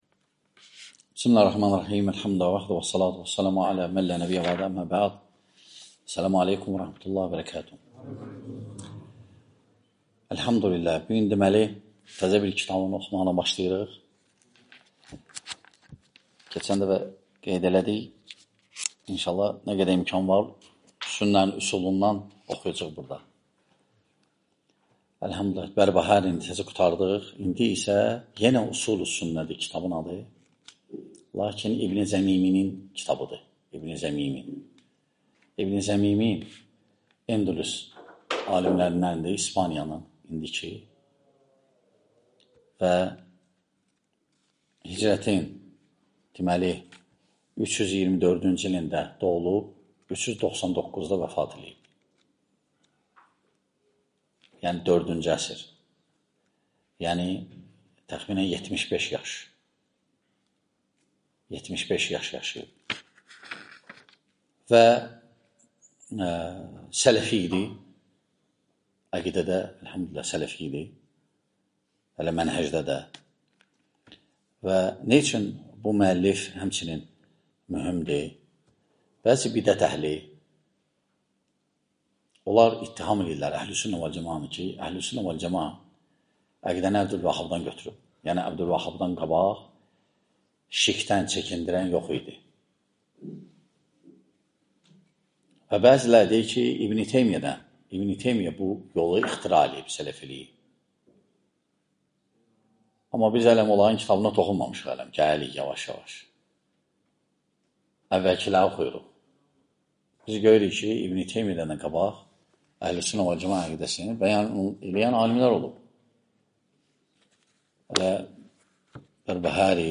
İmam ibn əbi Zəməninin «Üsulu Sünnə» kitabının şərhi (15 dərs)